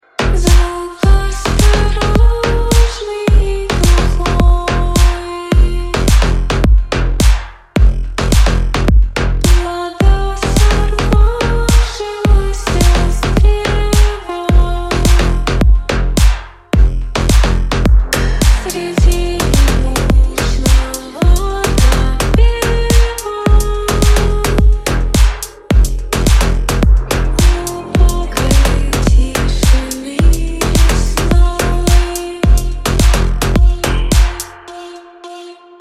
• Качество: 128, Stereo
саундтреки
музыка из игр